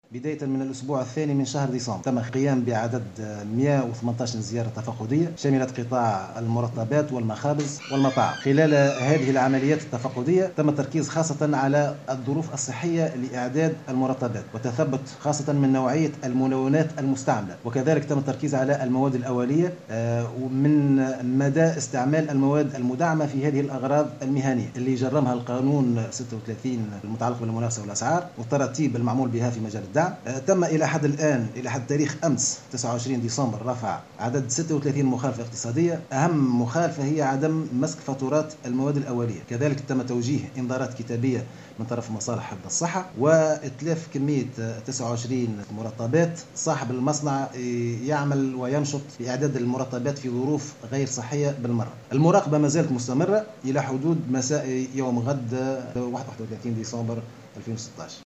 وأكد المدير الجهوي للتجارة بالمهدية، الحبيب النصري في تصريح لمراسل "الجوهرة أف أم" بالجهة أنه تم تحرير 36 مخالفة اقتصادية من أجل عدم مسك فواتير مواد أولية، إضافة إلى إتلاف 29 قطعة مرطبات غير صالحة للاستهلاك.